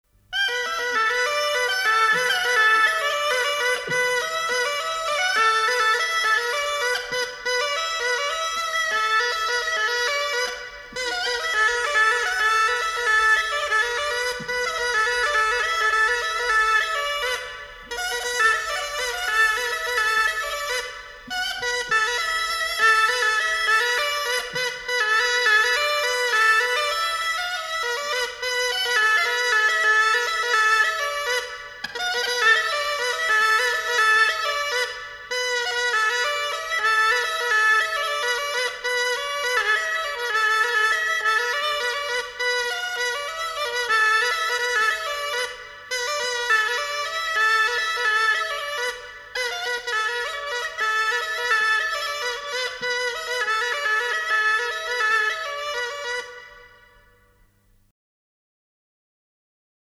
Голоса уходящего века (Курское село Илёк) Ивашка (рожок, инструментальный наигрыш)